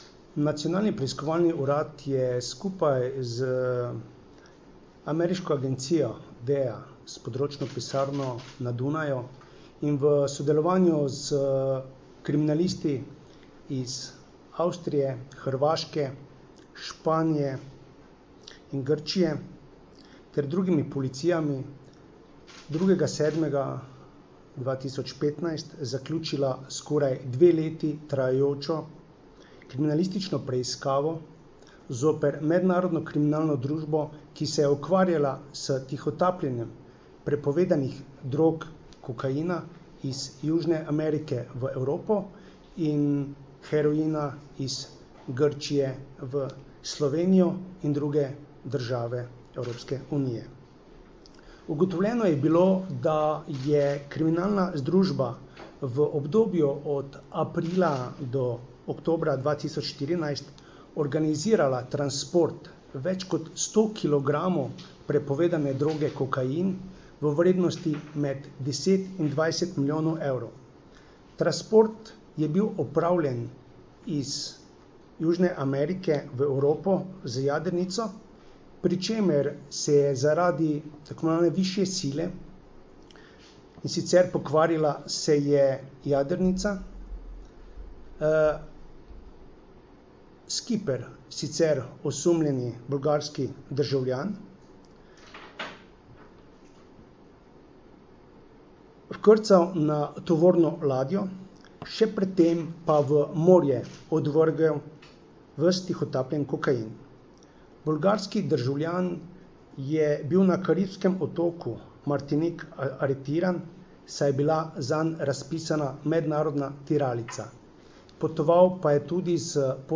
Na današnji novinarski konferenci smo podrobneje predstavili uspešen zaključek dlje časa trajajoče in obsežne preiskave mednarodne razsežnosti oz. več sočasnih in medsebojno prepletenih kriminalističnih preiskav zoper tri med sabo povezane hudodelske združbe, ki so v Sloveniji in v tujini izvrševale kazniva dejanja s področja prepovedanih drog.
Zvočni posnetek izjave Darka Majheniča, (mp3)
direktor Nacionalnega preiskovalnega urada